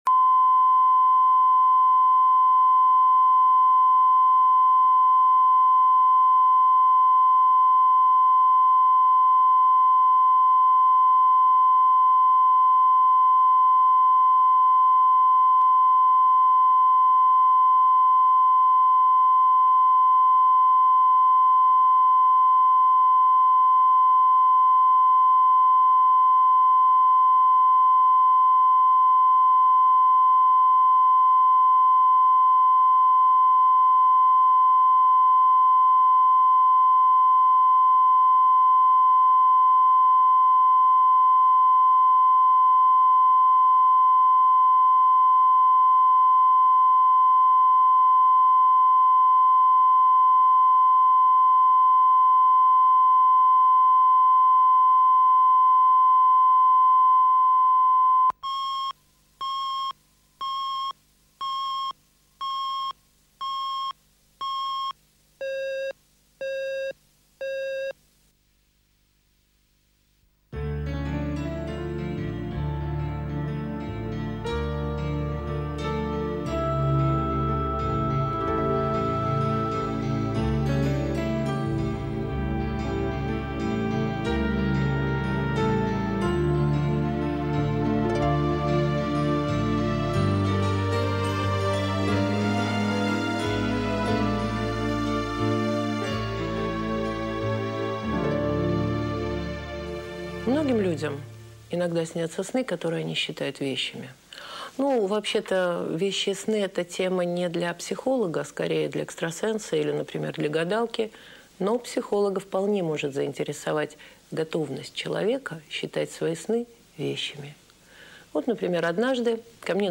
Аудиокнига Заглянуть за горизонт | Библиотека аудиокниг